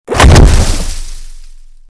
attack.mp3